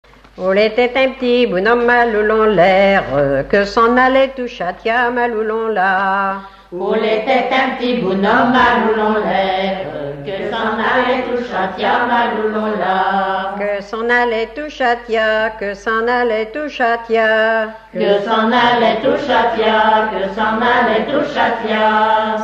Genre laisse
repertoire de chansons traditionnelles
Catégorie Pièce musicale inédite